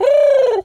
pigeon_call_angry_10.wav